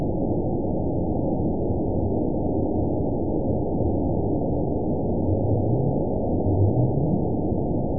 event 922783 date 04/05/25 time 05:05:41 GMT (2 months, 2 weeks ago) score 9.37 location TSS-AB02 detected by nrw target species NRW annotations +NRW Spectrogram: Frequency (kHz) vs. Time (s) audio not available .wav